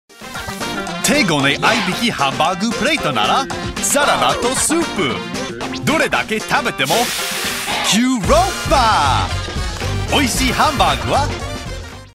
"Foreign-sounding" Japanese
While most of my work is in English, I am also fluent in Japanese and often take part in projects which want fluent but "foreign" Japanese.
Foreign Accent Japanese.mp3